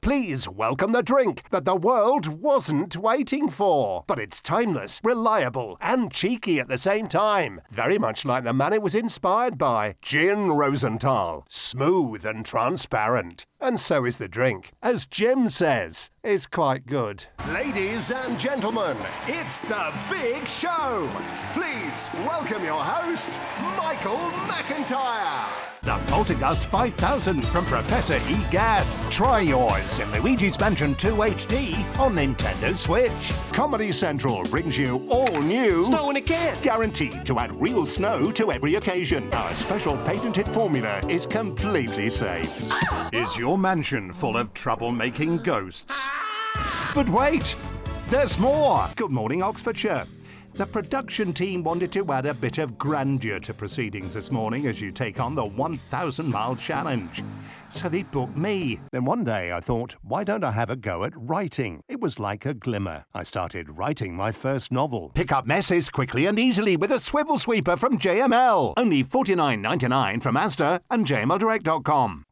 Voice of God
Commercial
Promo